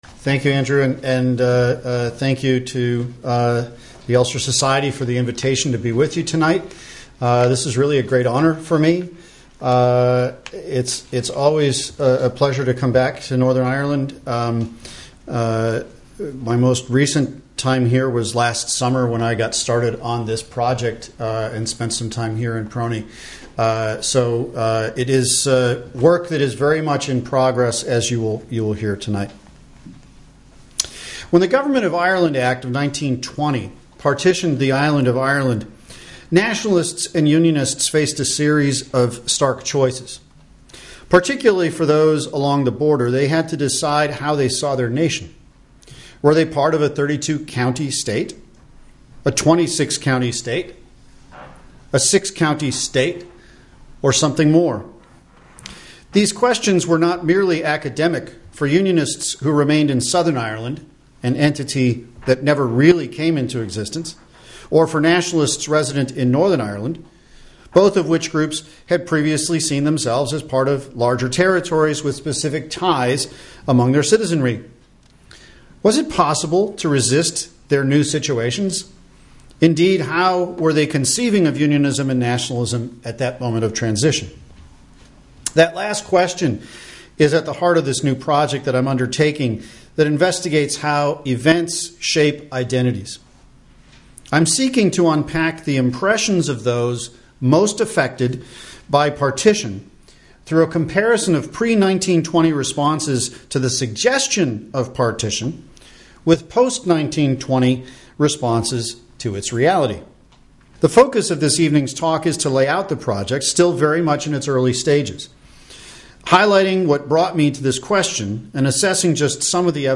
A free USIHS Lecture: Thursday 19 April 2018 at 7.00pm
Public Record Office of Northern Ireland, Seminar Room
This talk is based on new research relating to the Irish border. It draws upon testimonies and submissions to the 1920s Boundary Commission to test out some of Rogers Brubaker’s theoretical approaches to identity formation, most especially from his book Ethnicity without Groups. This is a public event, open to all.